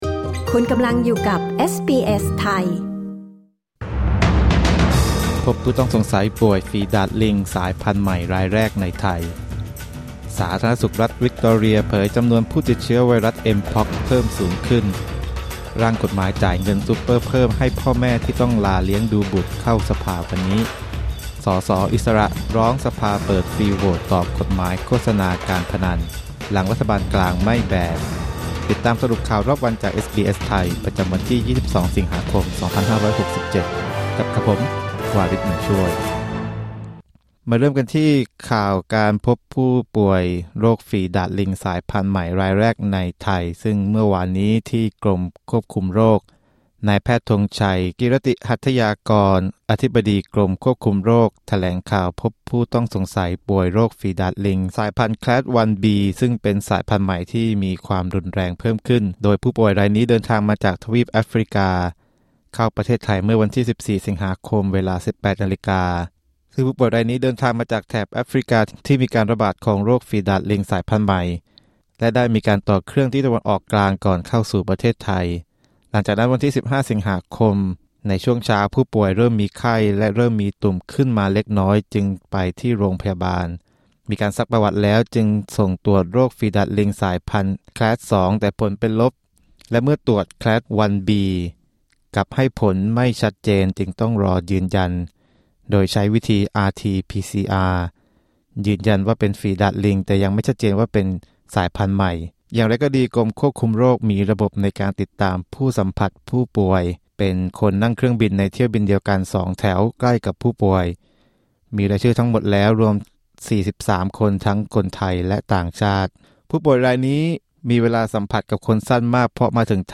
สรุปข่าวรอบวัน 22 สิงหาคม 2567